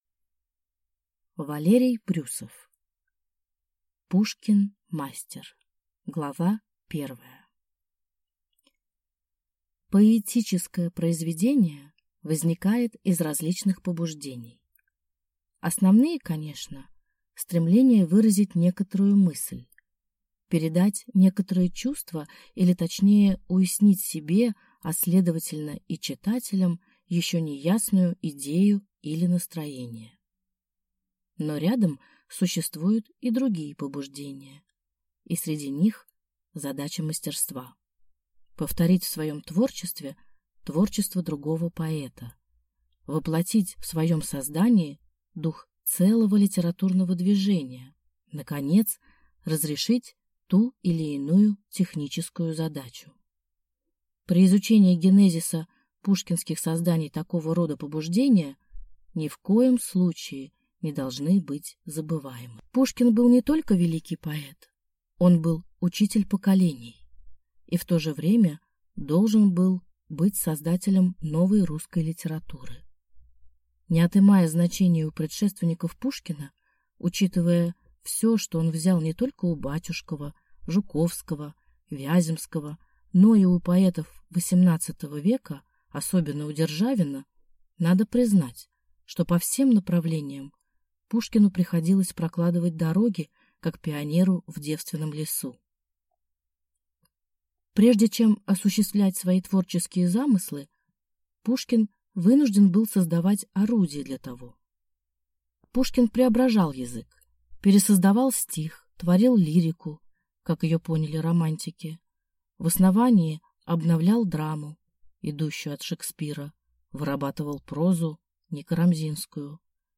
Аудиокнига Пушкин-мастер | Библиотека аудиокниг